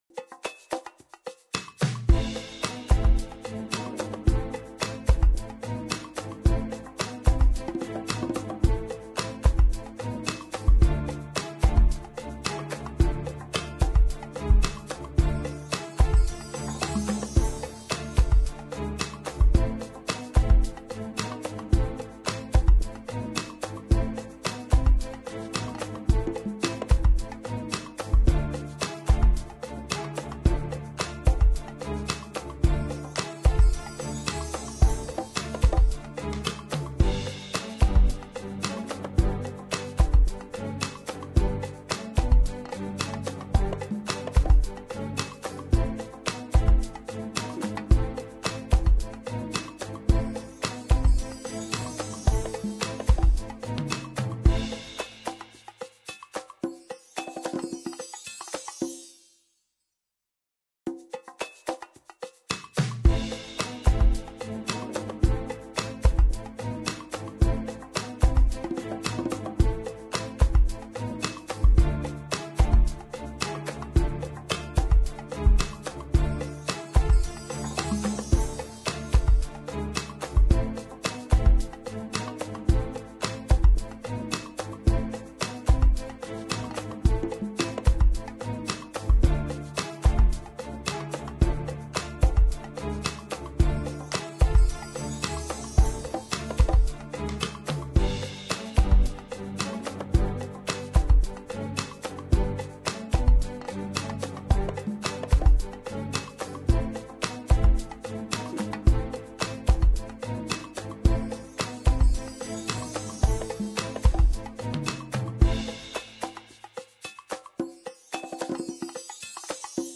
Эфир ведет Татьяна Фельгенгауэр